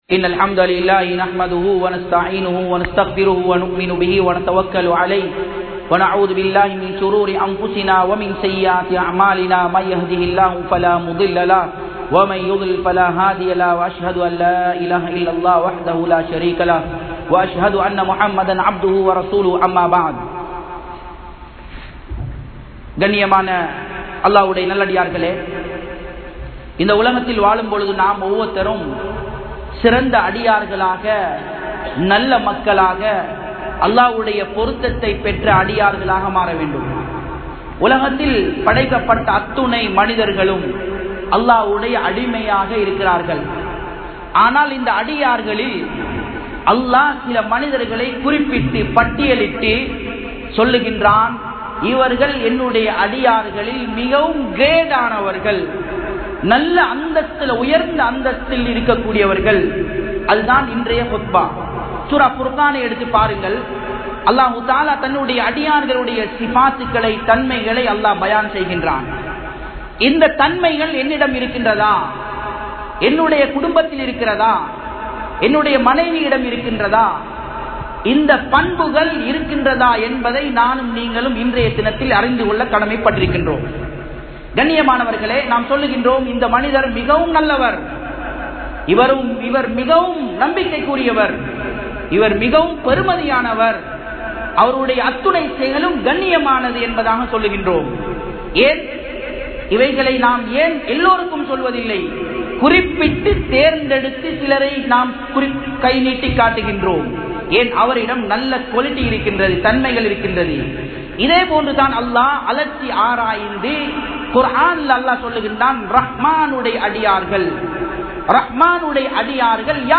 Manitharhalil Siranthavarhal (மனிதர்களில் சிறந்தவர்கள்) | Audio Bayans | All Ceylon Muslim Youth Community | Addalaichenai
Japan, Nagoya Port Jumua Masjidh 2017-08-11 Tamil Download